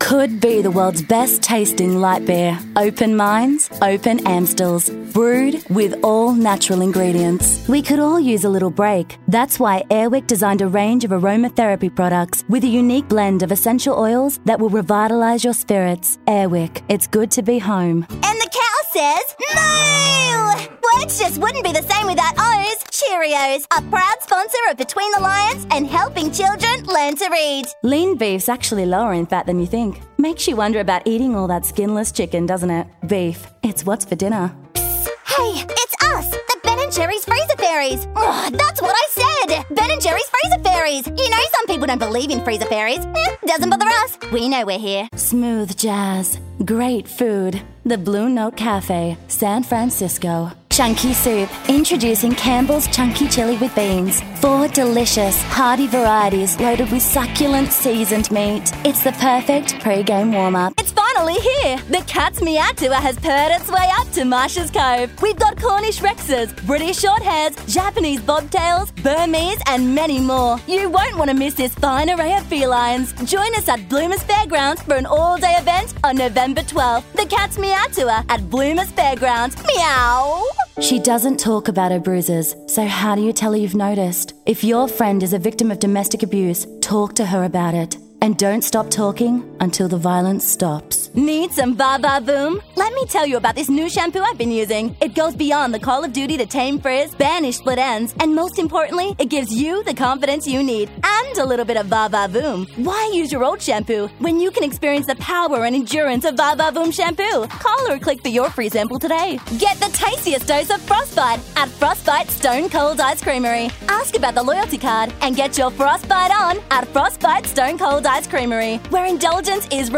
Female
English (North American), English (Australian)
Teenager (13-17), Yng Adult (18-29)
Radio Commercials
Commercial Demo Reel